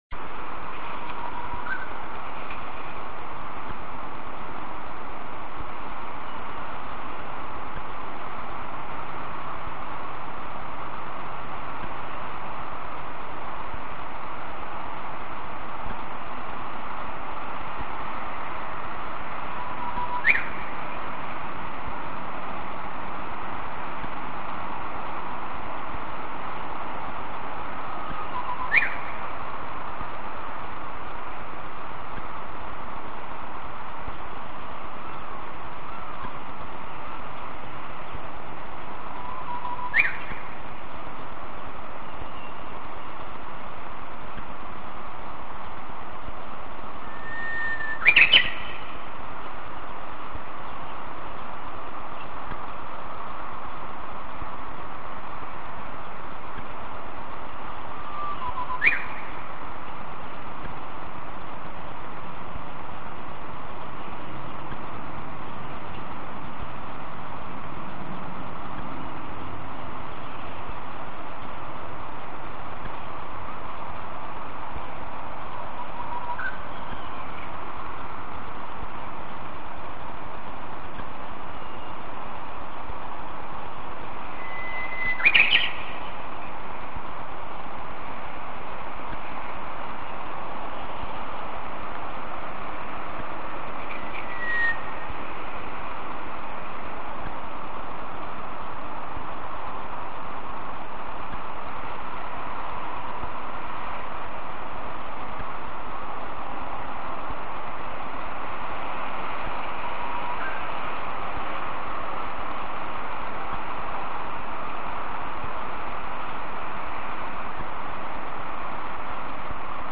タグ「ウグイス」が付けられているもの
数日前のことなんですが、目の前の川原にある藪から、なんとウグイスの声が聞こえるではありませんか。
とはいえ携帯プレーヤで簡易的に録音したので大したものではありませんが、よろしければどうぞ。